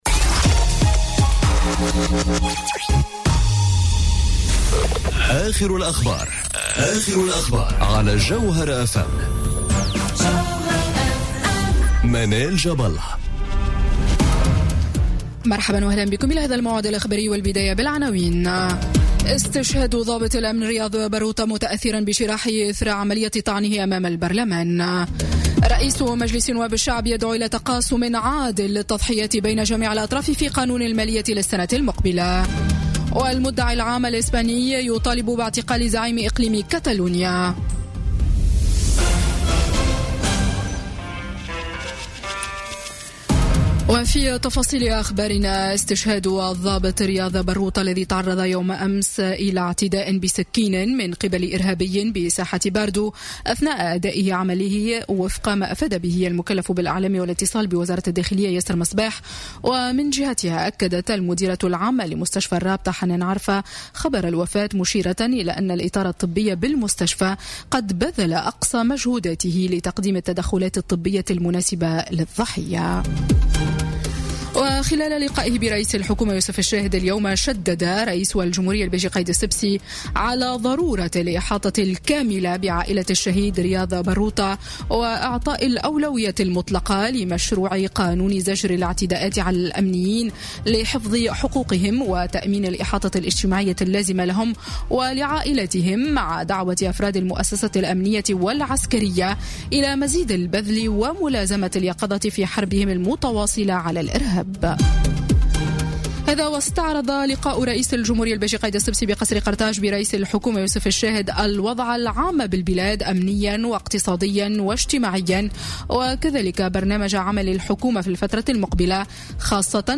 نشرة أخبار السابعة مساءً ليوم الخميس 2 نوفمبر 2017